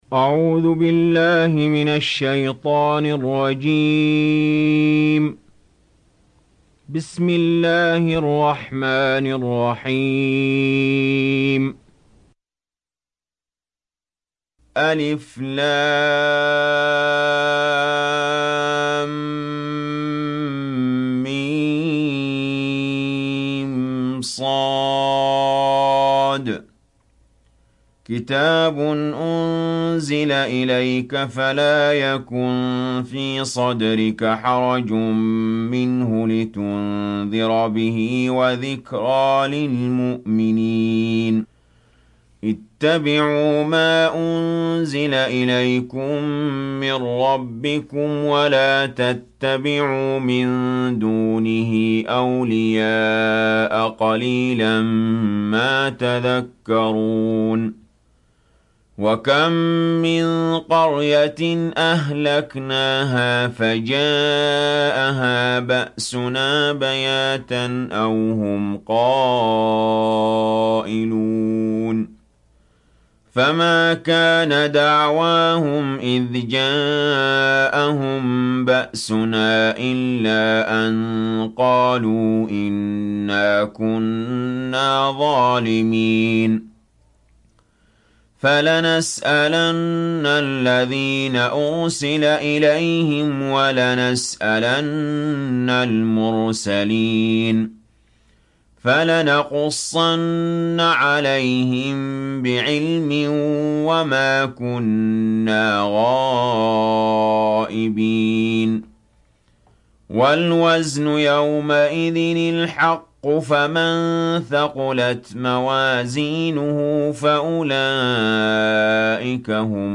Sourate Al Araf Télécharger mp3 Ali Jaber Riwayat Hafs an Assim, Téléchargez le Coran et écoutez les liens directs complets mp3